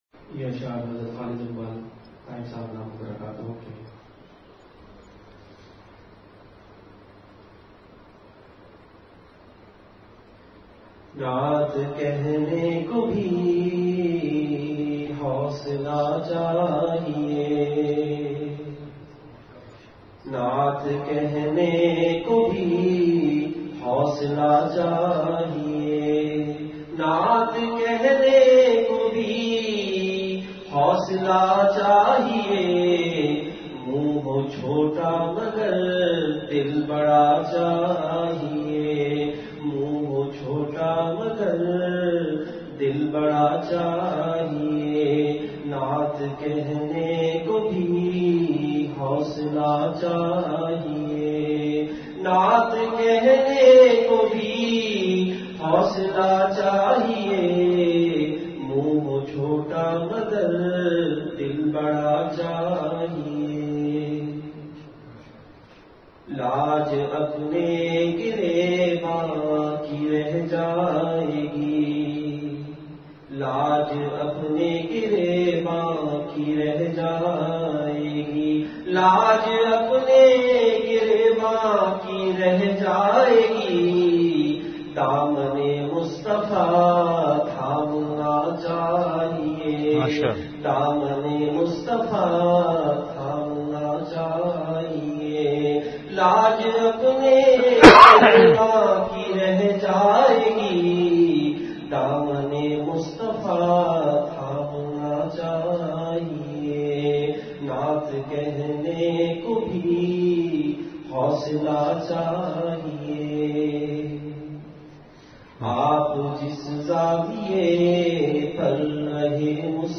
Delivered at Home.
Majlis-e-Zikr